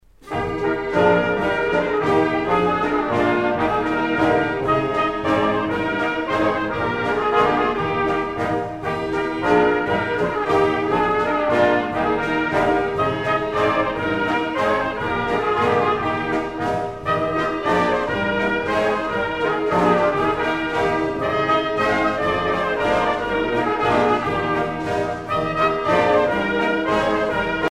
danse : ruchenitza (Bulgarie)
Pièce musicale éditée